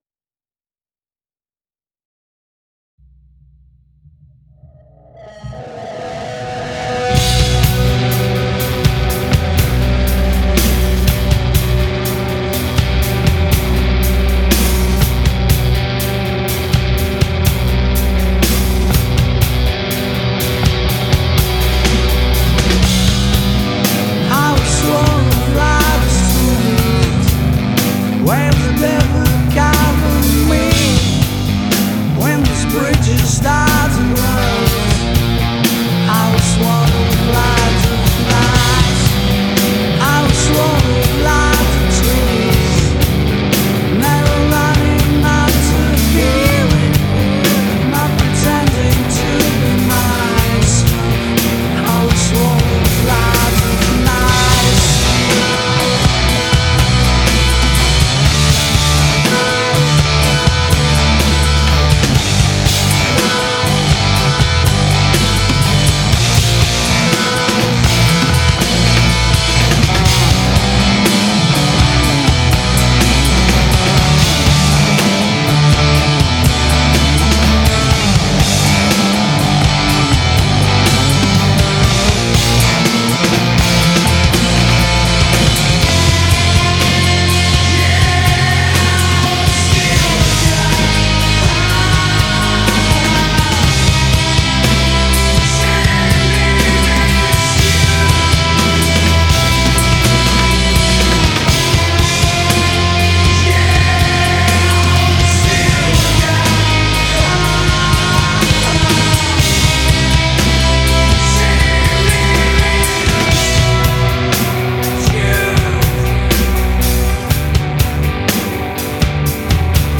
Doprovodný vocal